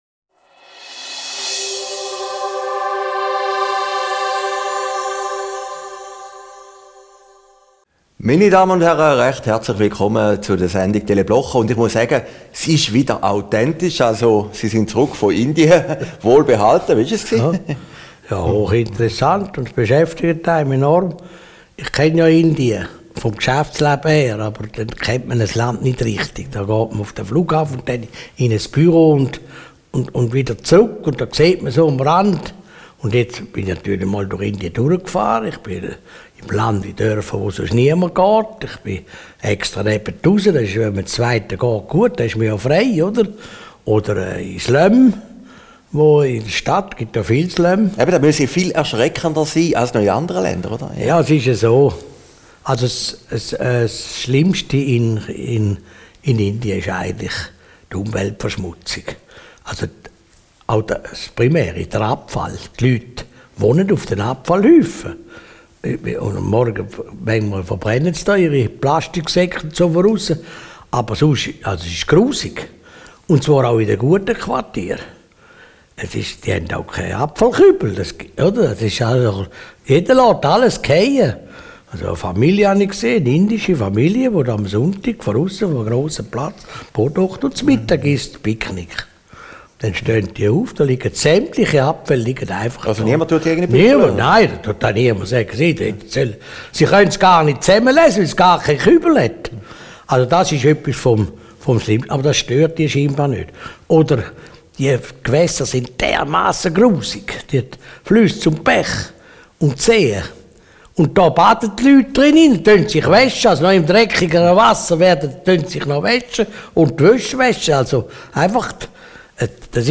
Video downloaden MP3 downloaden Christoph Blocher über seine Rückkehr aus Indien, den Schweizer Politbetrieb und den Fall Mörgeli Aufgezeichnet in Herrliberg, 23.